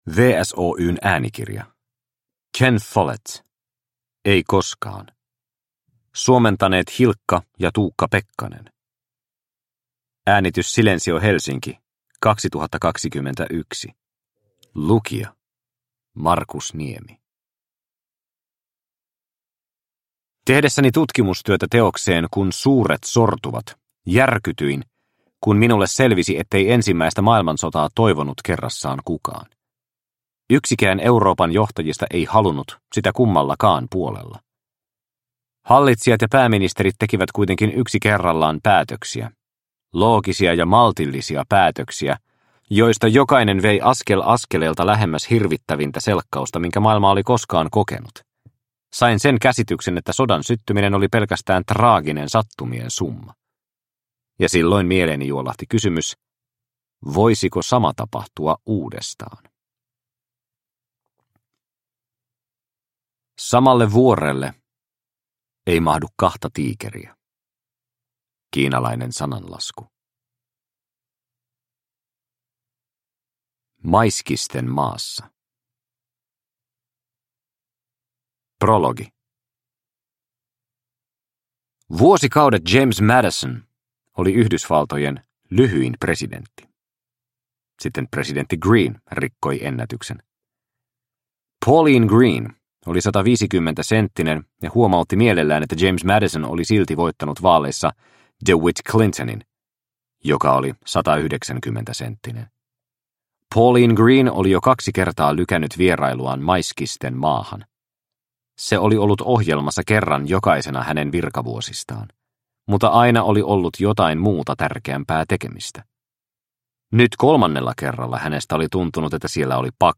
Ei koskaan – Ljudbok – Laddas ner